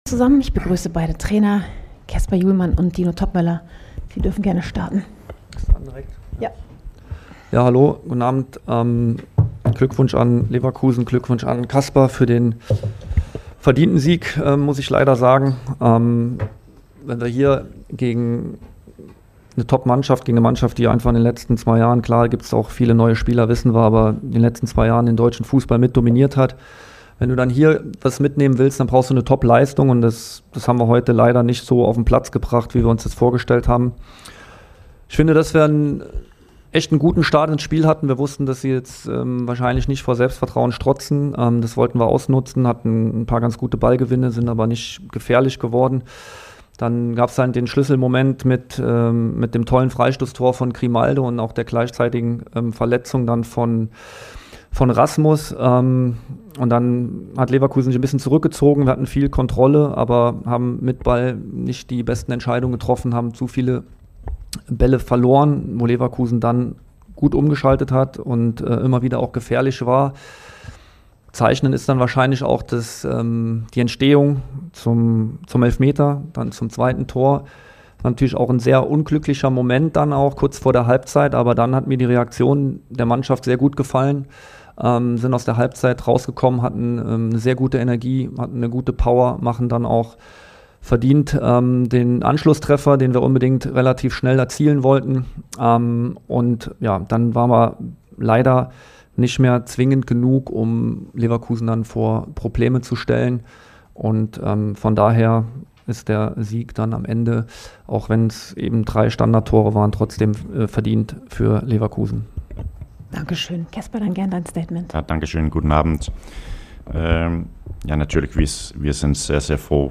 "Verdiente Niederlage" I Pressekonferenz nach Bayer Leverkusen - Eintracht
"Eintracht Aktuell" - Stimmen aus der Eintracht Welt